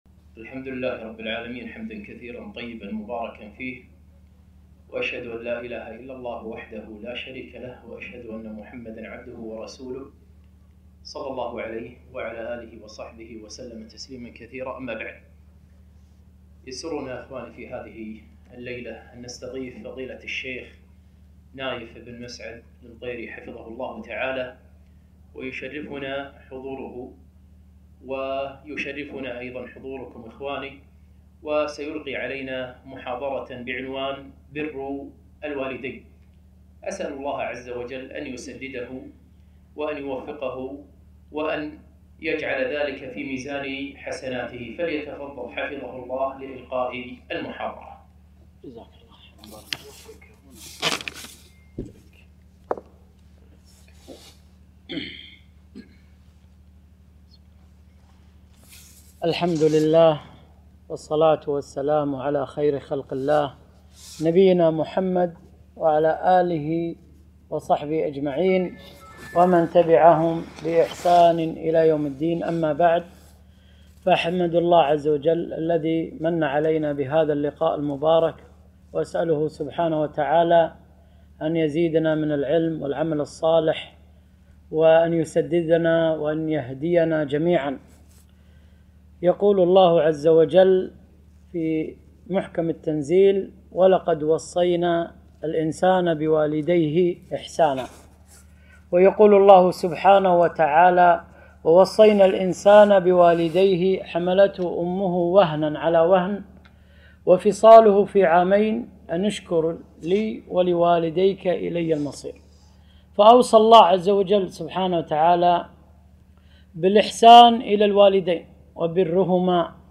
محاضرة - بـر الـوالـديـن